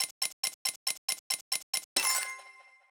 Prize Wheel Spin 1 (long).wav